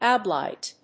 音節ou・bli・ette 発音記号・読み方
/ùːbliét(米国英語), uːblɪˈɛt(英国英語)/